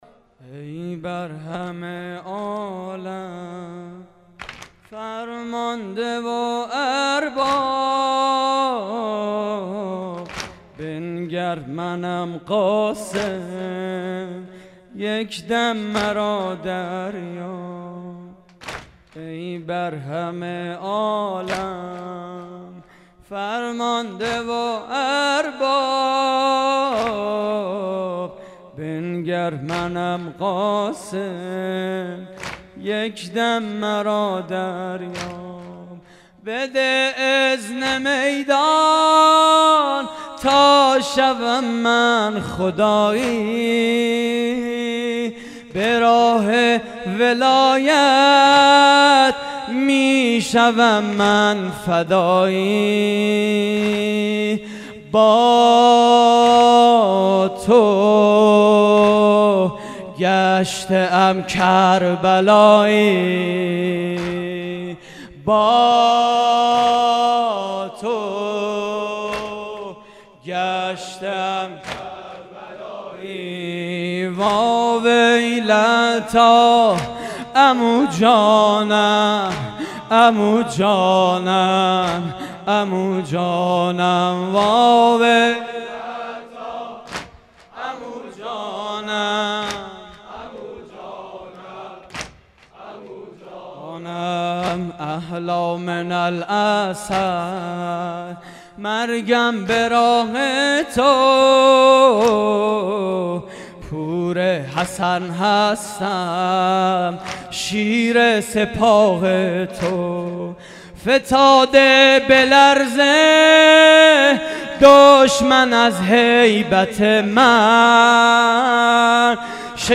مراسم عزاداری شب ششم ماه محرم